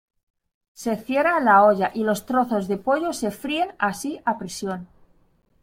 Read more Noun Adj Opposite of oso Frequency B1 Hyphenated as po‧llo Pronounced as (IPA) /ˈpoʝo/ Etymology Inherited from Latin pullus In summary Inherited from Latin pullus, from Proto-Indo-European *polH- (“animal young”).